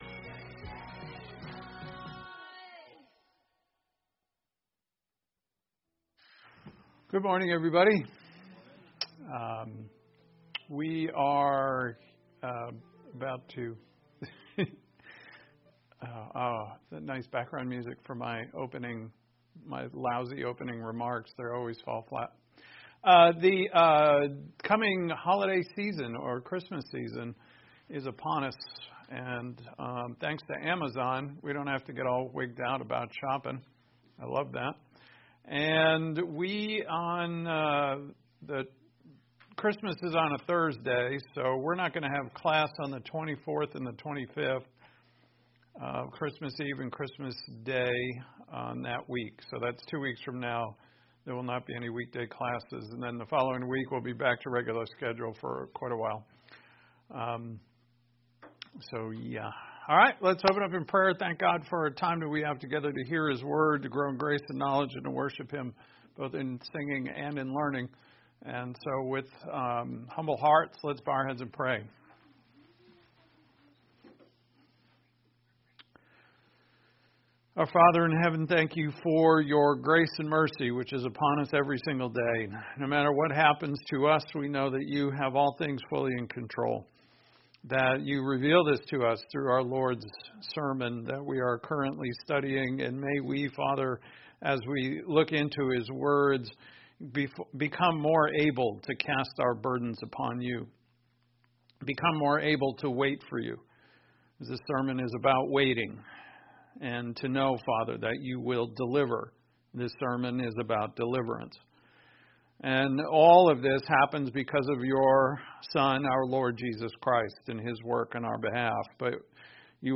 Intro: Without an understanding of dispensations, this sermon is very difficult to interpret.